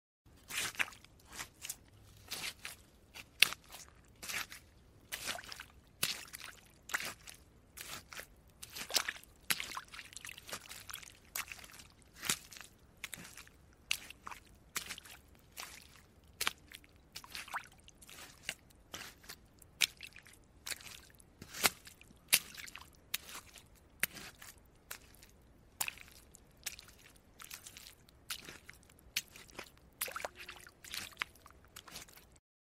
Tiếng Bước Chân đi trên Bùn lầy, Vũng nước…
Thể loại: Tiếng động
Description: Tiếng bước chân trên bùn lầy, vũng nước, tiếng lội bùn, tiếng dẫm bùn, tiếng đạp nước, lẹp bẹp bì bõm, nhóp nhép sình sụp, rất hợp làm hiệu ứng âm thanh (Footsteps in Swamp Mud Sound Effect). Âm sắc ẩm ướt, nặng nề, dính kéo, từng nhịp lép nhép bõm bõm tạo cảm giác lạnh ẩm, hiểm trở.
tieng-buoc-chan-di-tren-bun-lay-vung-nuoc-www_tiengdong_com.mp3